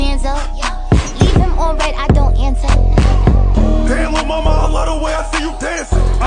It’s so hard to sing with a Scottish accent